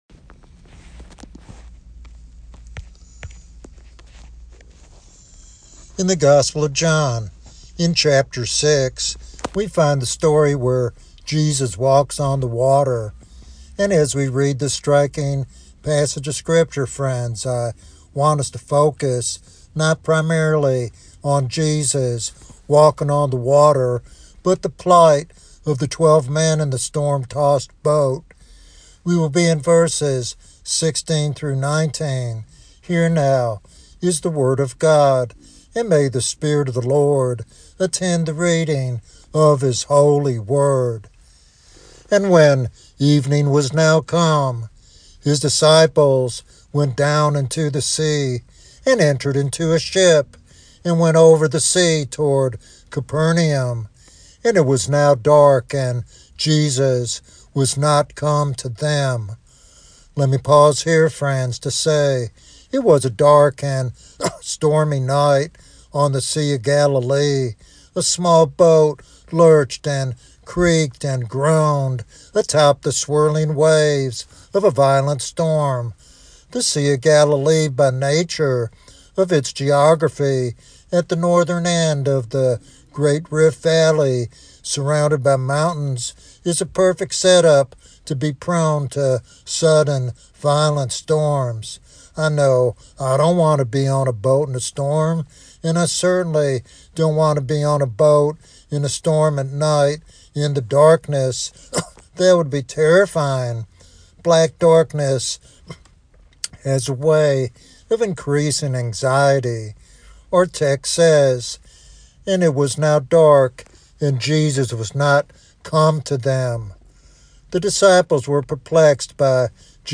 In this devotional sermon